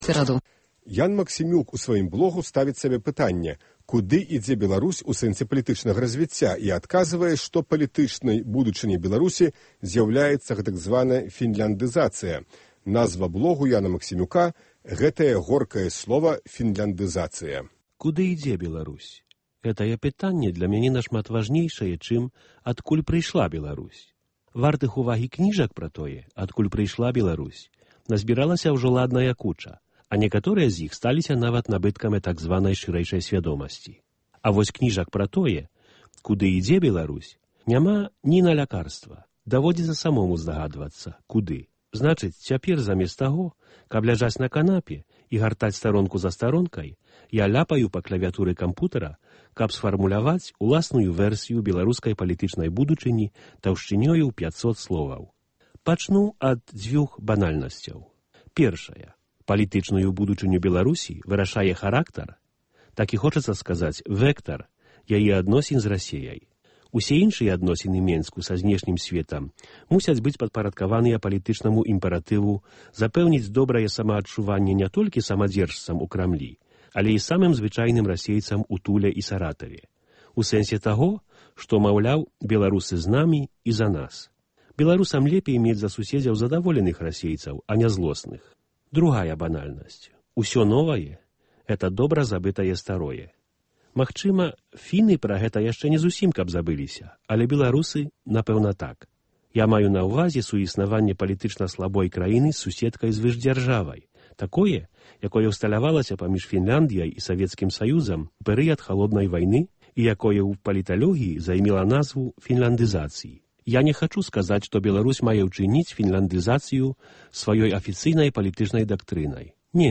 Нашы блогеры чытаюць свае тэксты